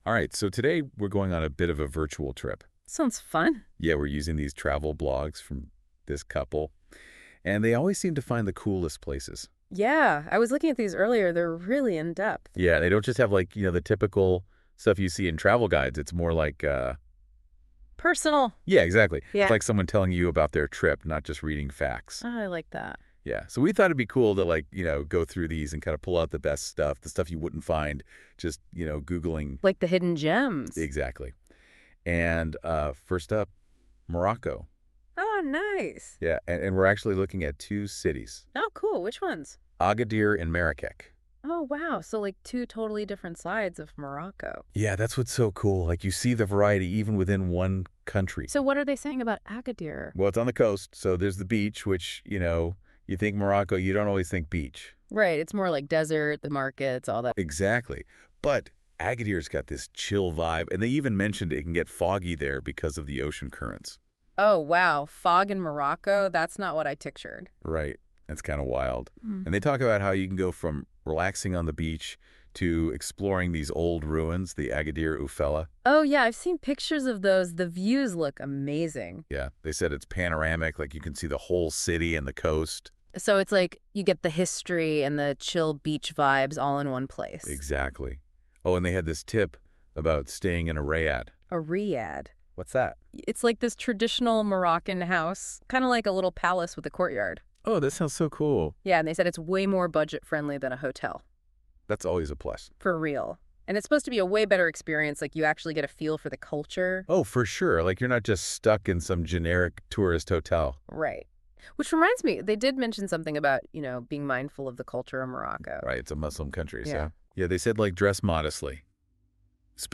A cartoon image of a studio PodCast conversation link to our audio Podcast.